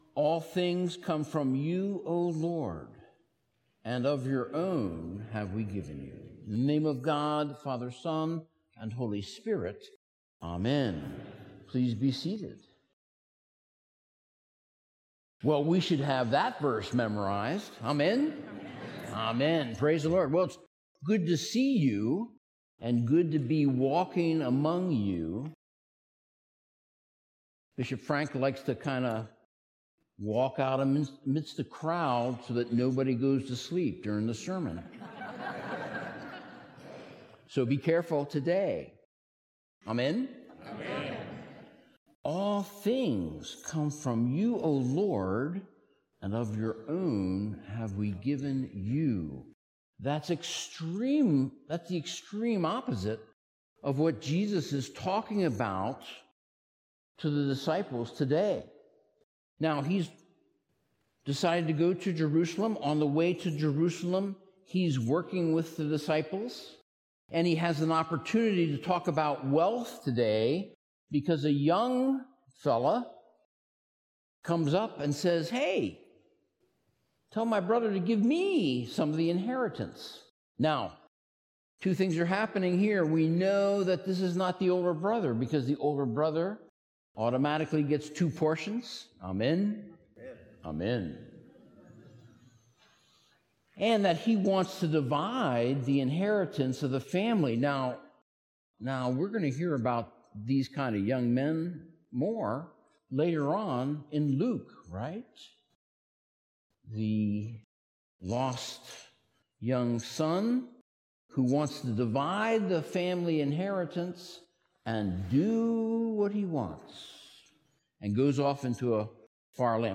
Sermons - Holy Cross Anglican Cathedral
Related Topics: Bishop Visitations | More Sermons from The Rt.